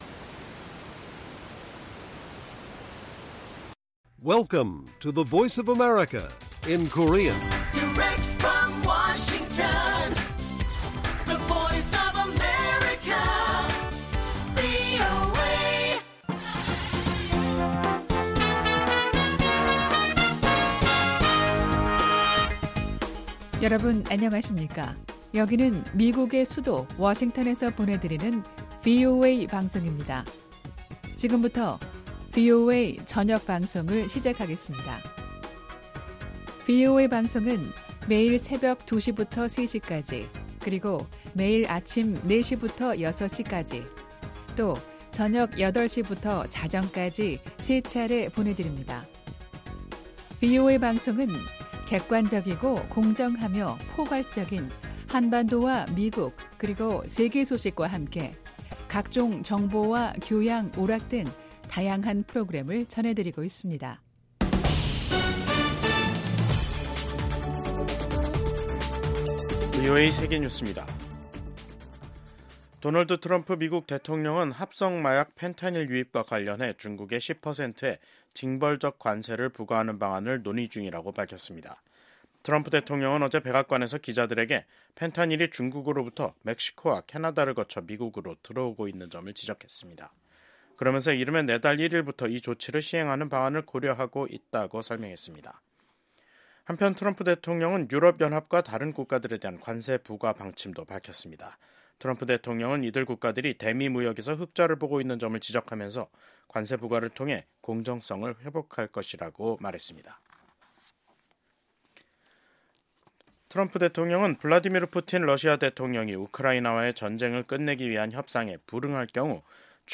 VOA 한국어 간판 뉴스 프로그램 '뉴스 투데이', 2025년 1월 22일 1부 방송입니다. 미국의 외교·안보 전문가들은 도널드 트럼프 대통령의 ‘북한 핵보유국’ 발언이 북한의 현실을 언급할 것일뿐 핵보유국 지위를 인정하는 것은 아니라고 분석했습니다. 트럼프 대통령의 취임 직후 대북 메시지가 협상 재개를 염두에 둔 유화적 신호에 그치지 않고 김정은 국무위원장을 압박하는 이중적 메시지라는 관측이 나옵니다.